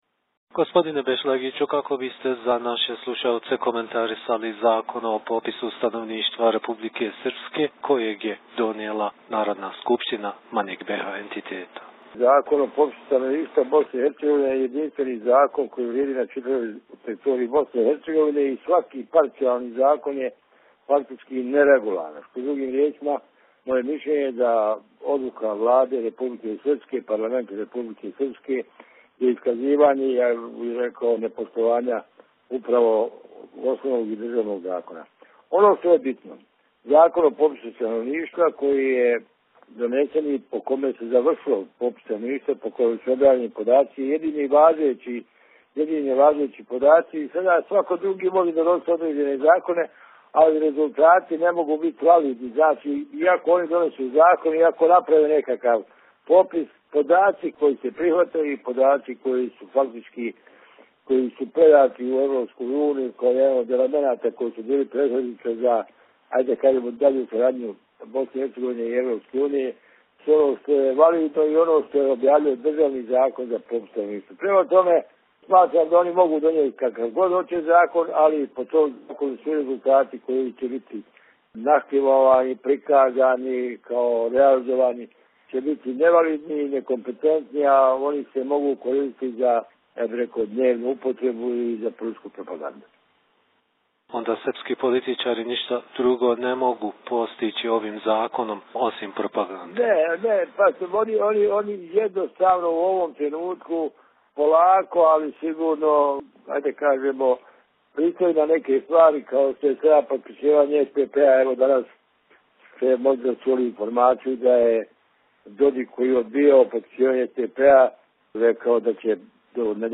Selim Bešlagić, politički analitičar